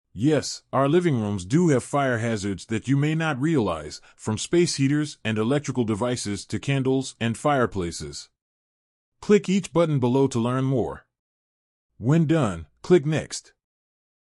Text-to-Speech Audio for Narration
We use AI-generated text-to-speech audio to narrate digital learning.